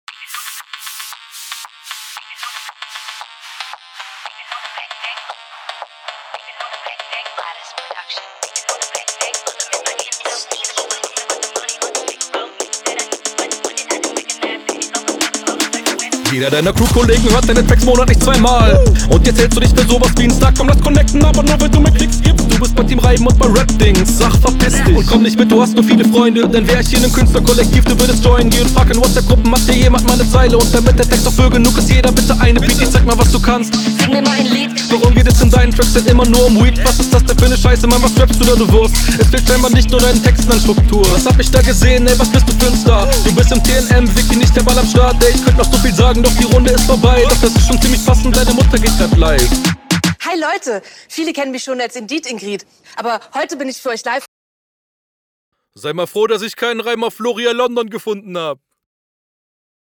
Soundquali halt nicht so geil, darunter leidet bisi die Verständlichkeit und Flow wirkt auch nicht …
wirkt leider etwas gehetzt und mischtechnisch geht das im beat unter, aber ist natürlich meckern …